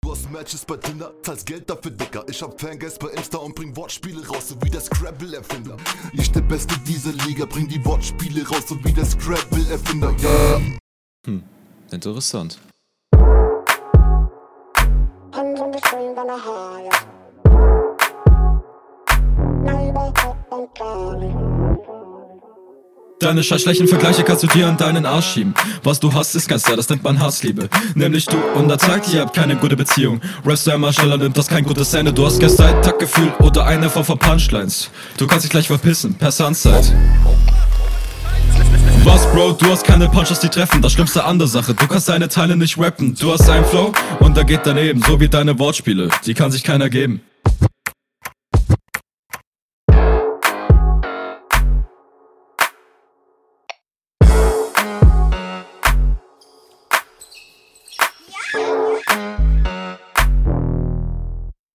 Beat ist cool. Der Rapstyle da drauf gefällt mir und ist ganz interessant.